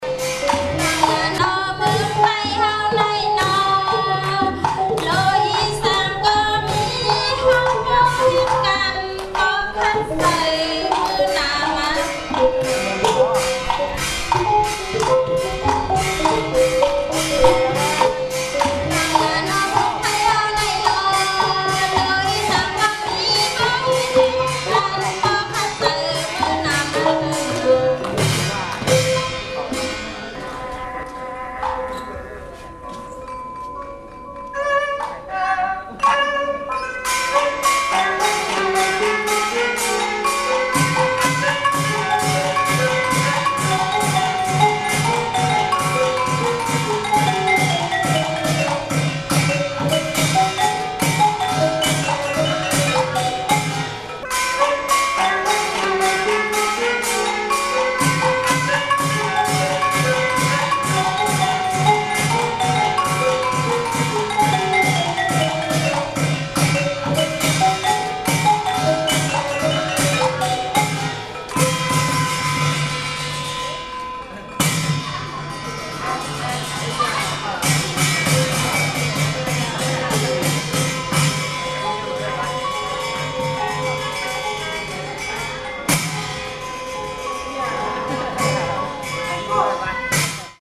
Shan music
drama performance the female singer gives way to a mainly percussion band; recorded in Chiang Mai university 1.4MB